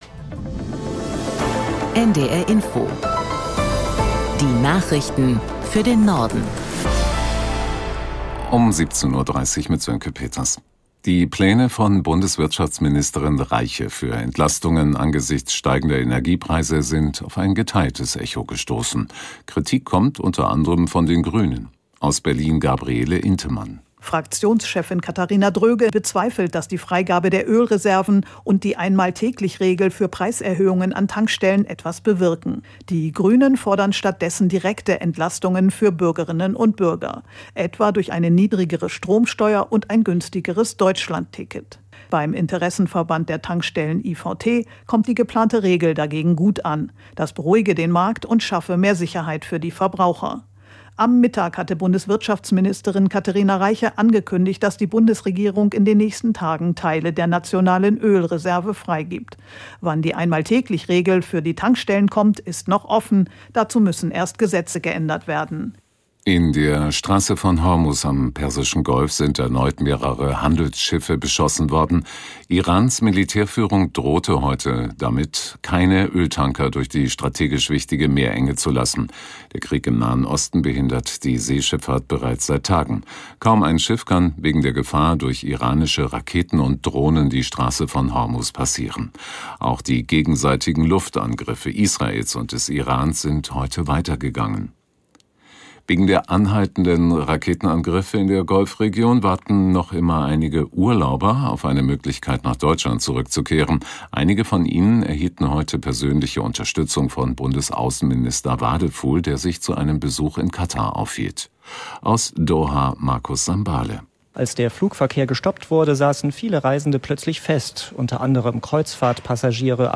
NDR Info Nachrichten